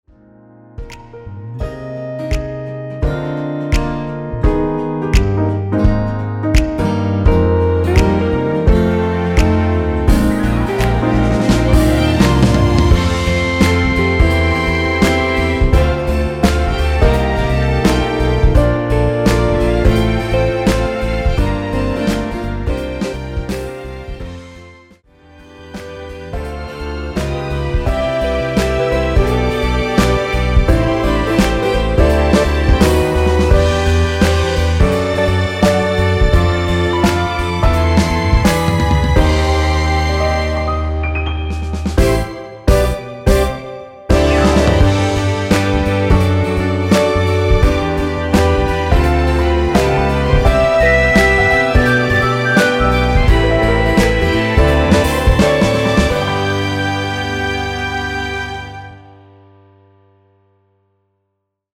엔딩이 페이드 아웃이라 노래 하기 좋게 엔딩을 만들어 놓았습니다.(미리듣기 참조)
원키에서(+2)올린(짧은편곡)MR입니다.
Eb
앞부분30초, 뒷부분30초씩 편집해서 올려 드리고 있습니다.
중간에 음이 끈어지고 다시 나오는 이유는